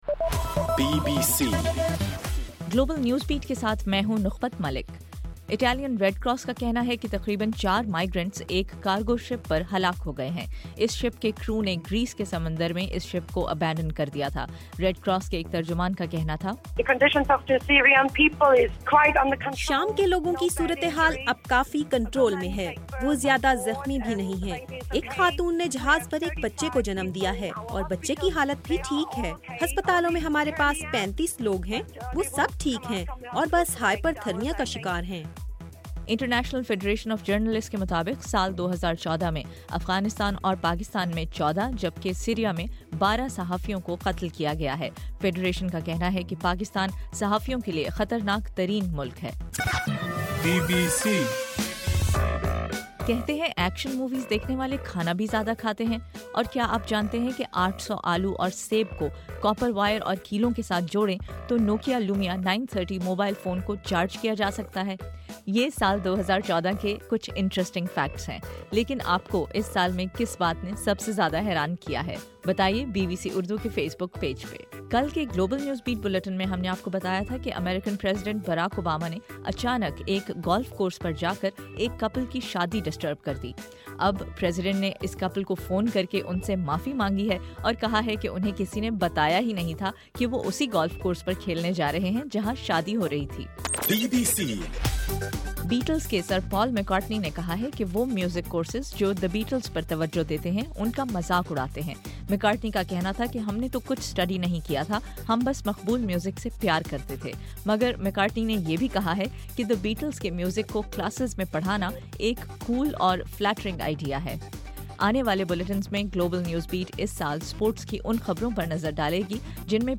دسمبر 31: رات 8 بجے کا گلوبل نیوز بیٹ بُلیٹن